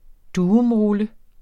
Udtale [ ˈduːɔm- ]